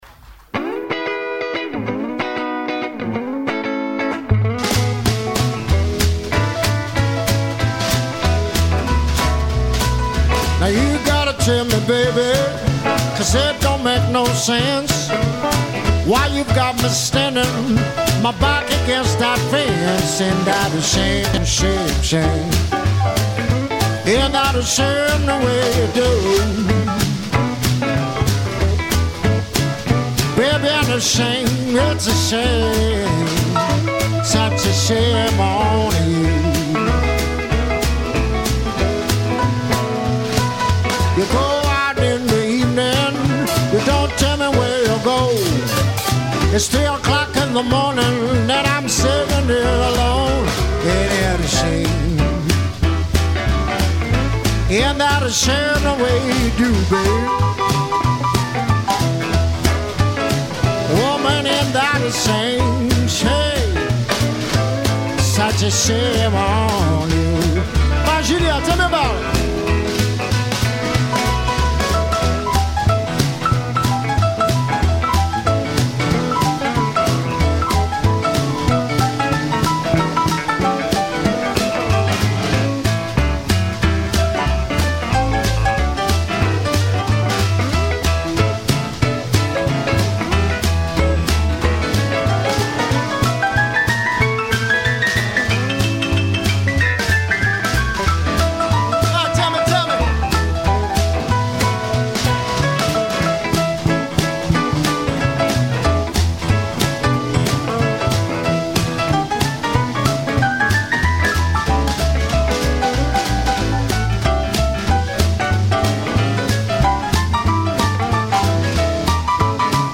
chant, harmonica
chant, piano, orgue
chant, contrebasse, harmonica
batterie